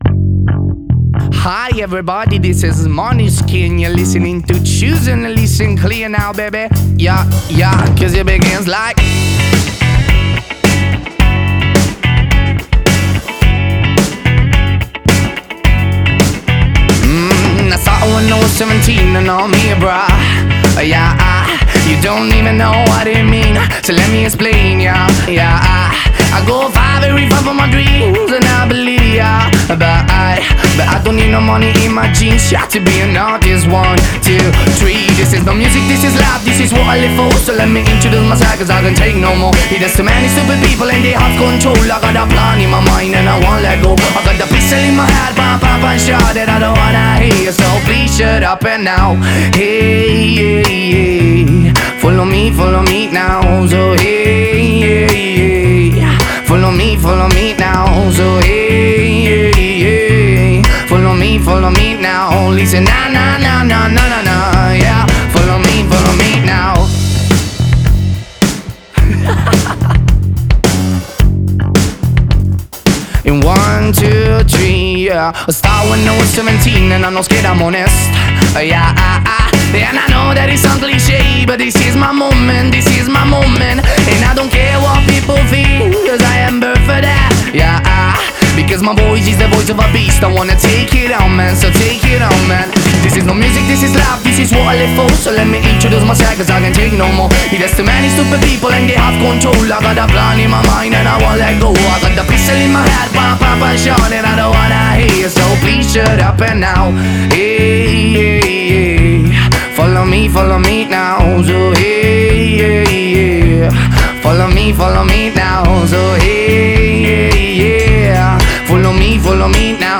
آهنگ راک